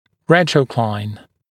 [‘retrə(u)klaɪn][‘рэтро(у)клайн]наклонять(ся) в небном/лингвальном направлении